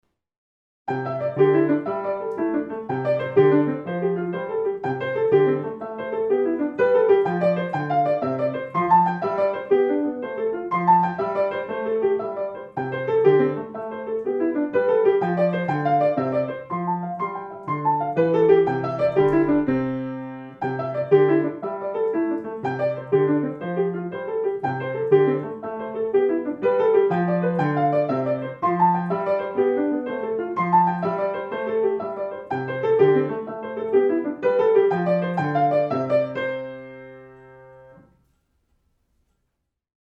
Musik zum Mantra 18 R — wüstenhaft-verödet